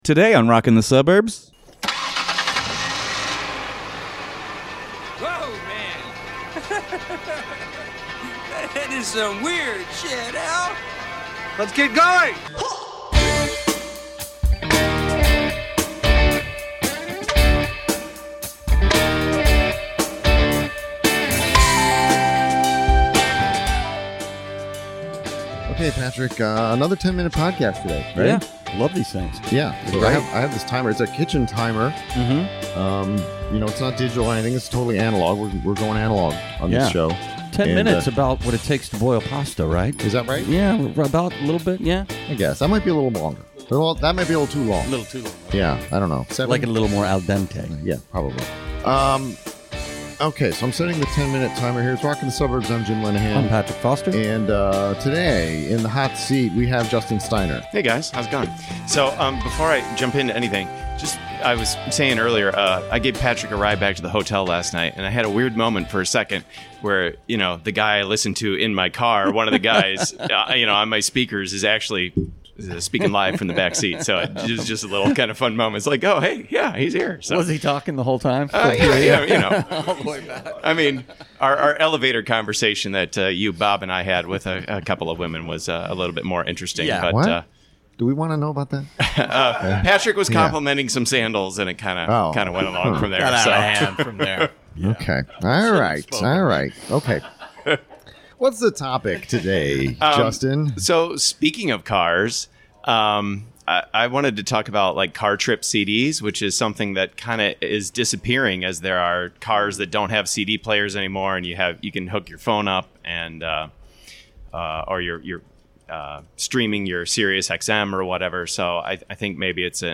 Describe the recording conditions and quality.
It's the fourth of the recordings we made on Day Two of Suburbs Fest Midwest! A crowd gathered at Arlington Ale House to join us and contribute.